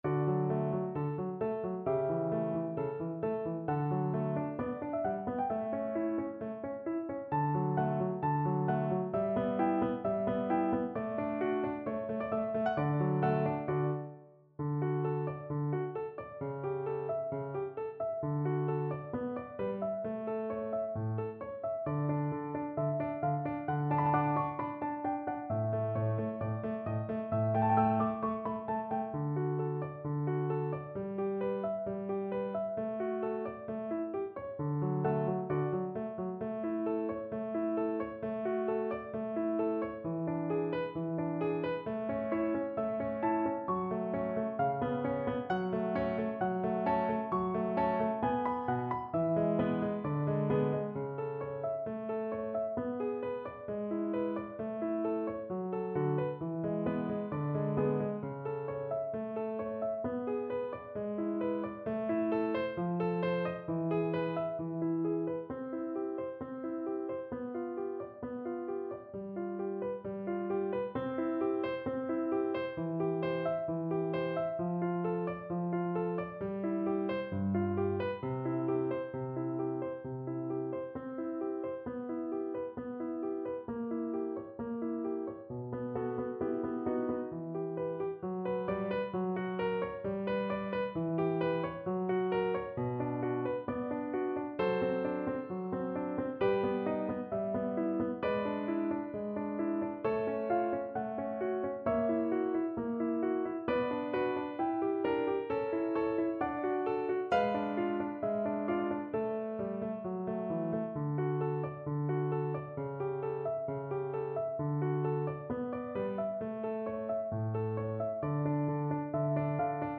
Symulacja akompaniamentu
mozart_voi-che-sapete_nuty_vn-pf-acc..mp3